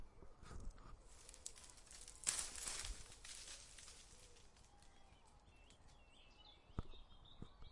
描述：叶子在不同的节奏下沙沙作响
Tag: 叶子 灌木 树叶 沙沙 沙沙